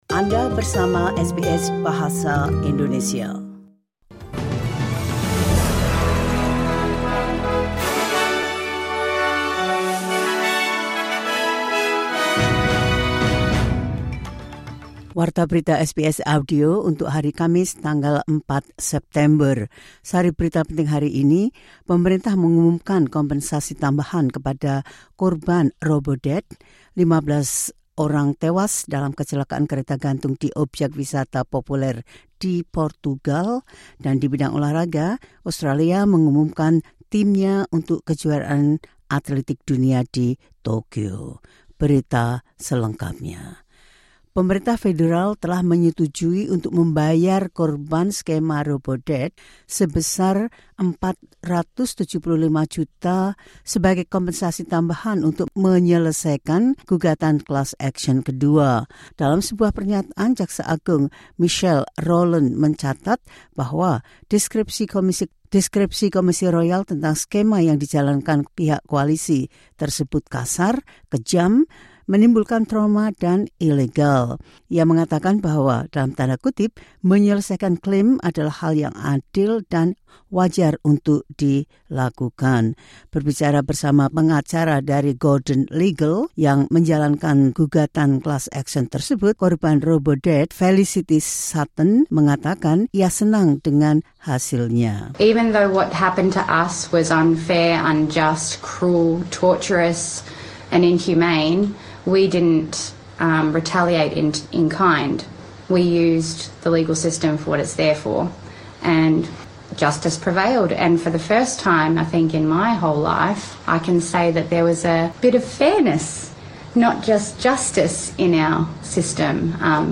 Berita terkini SBS Audio Program Bahasa Indonesia – 4 September 2025.
The latest news SBS Audio Indonesian Program – 04 September 2025.